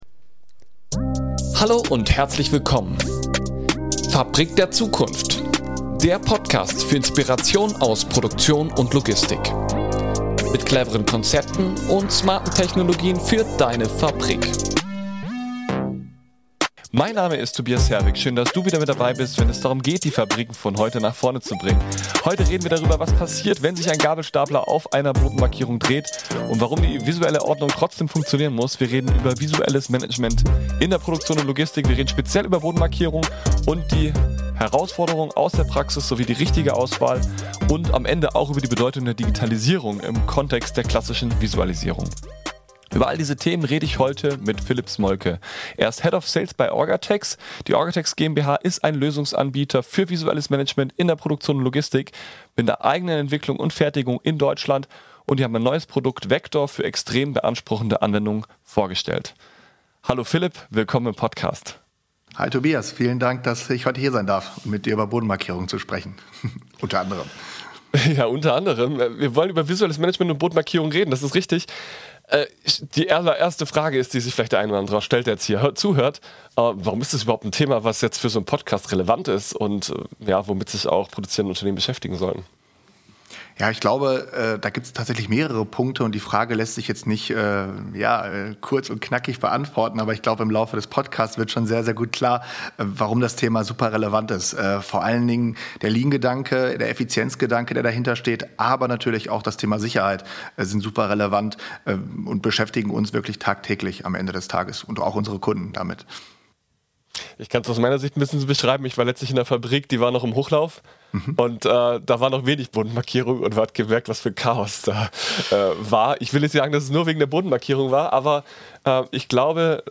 Sie diskutieren den unsichtbaren Helden des Shopfloors und wie innovative Lösungen auch extremen Belastungen standhalten. Bodenmarkierungen sind weit mehr als bunte Linien.